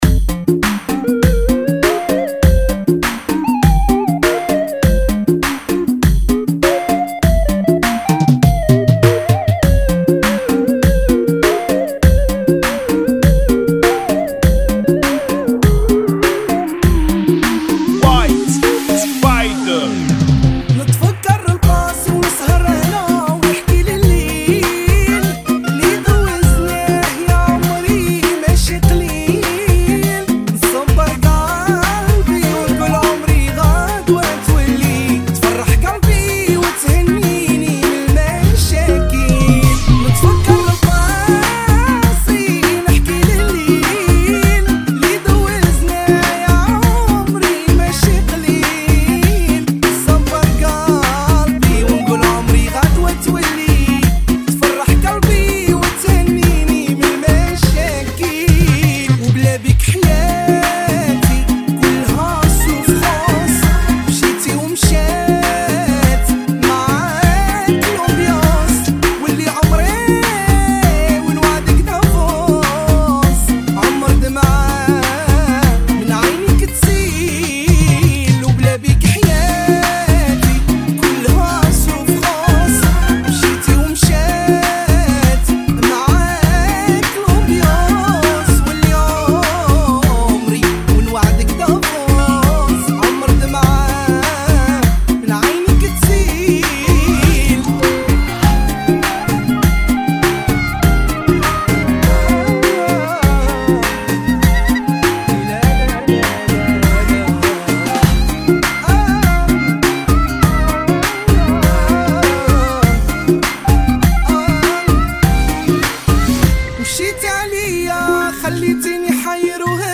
[ 100 Bpm ]